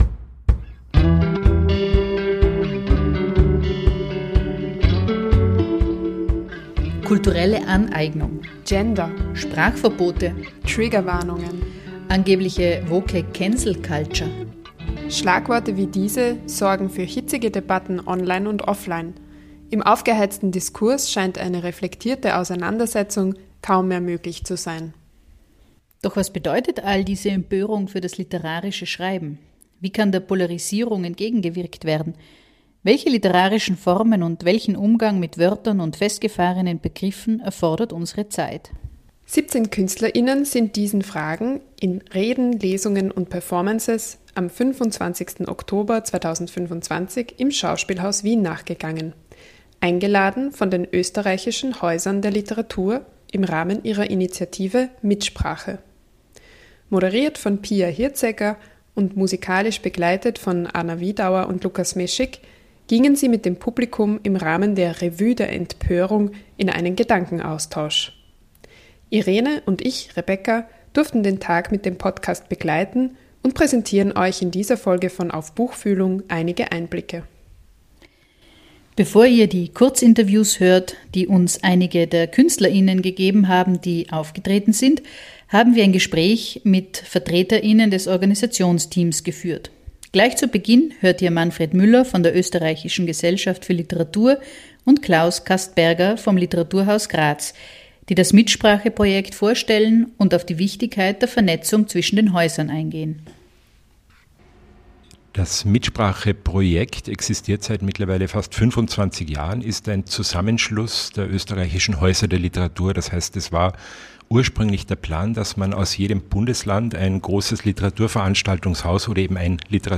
Welche literarischen Formen und welchen Umgang mit Wörtern und festgefahrenen Begriffen erfordert unsere Zeit? 17 Künstler*innen sind diesen Fragen in Reden, Lesungen und Performances am 25. Oktober 2025 im Schauspielhaus Wien nachgegangen, eingeladen von den Österreichischen Häusern der Literatur im Rahmen ihrer Initiative „mitSprache“. “Auf Buchfühlung” durfte den Tag begleiten und in dieser Folge geben wir euch einige Einblicke.